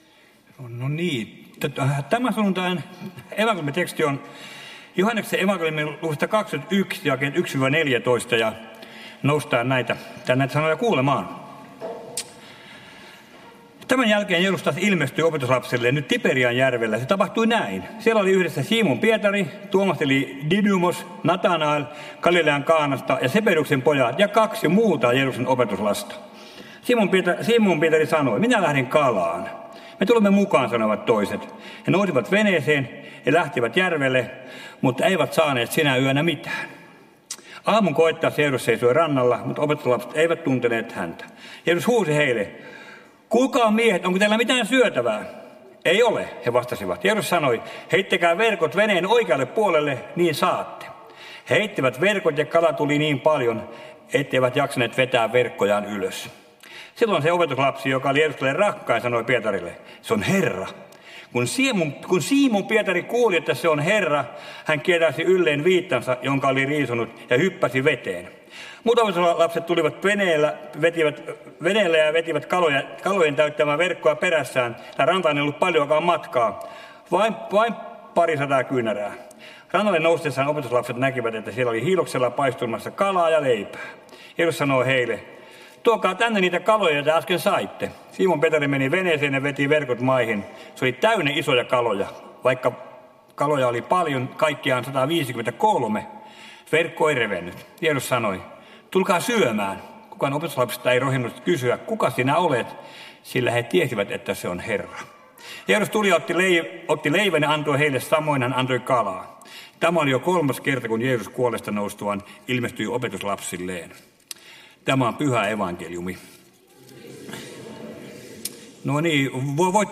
Kokoelmat: Tampereen Luther-talo